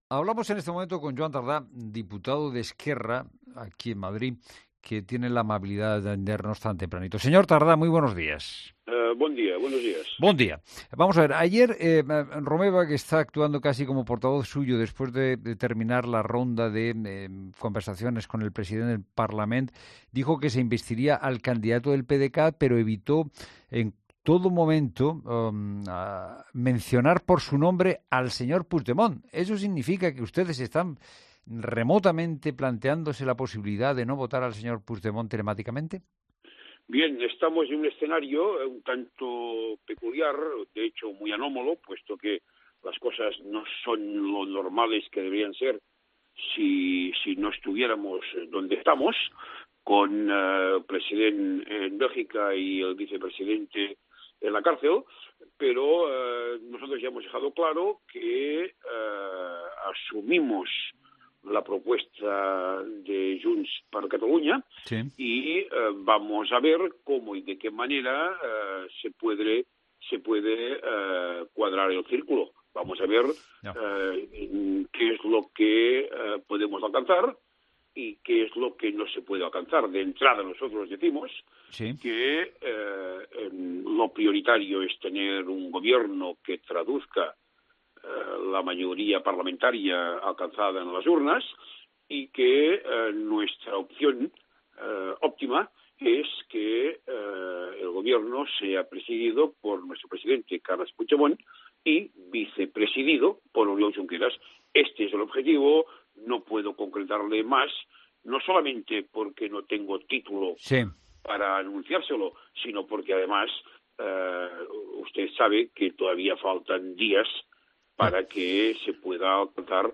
El portavoz de ERC en el Congreso de los Diputados, Joan Tardà, ha asegurado en 'La Mañana de Fin de Semana' que el objetivo de su formación política es restituir el anterior Gobierno catalán, pero ha admitido que está contemplando "todas las posibilidades", incluyendo una investidura sin el expresidente de la Generalitad de Cataluña, Carles Puigdemont.